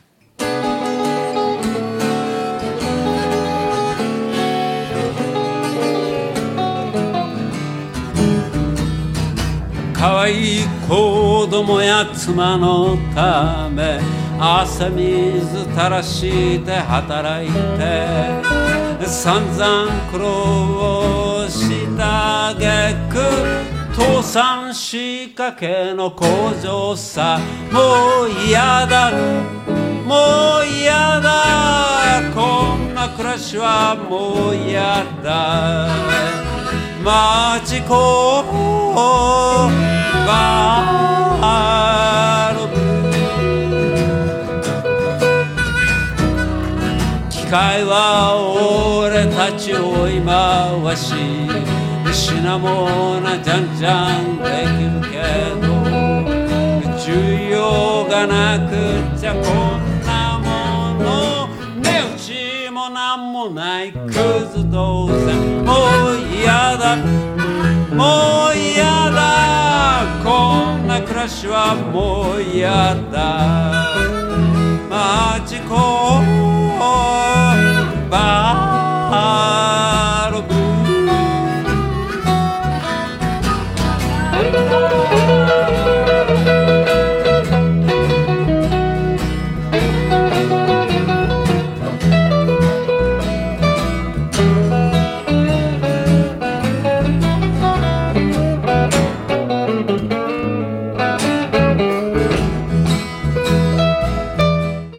鬼気迫る歌唱が◎！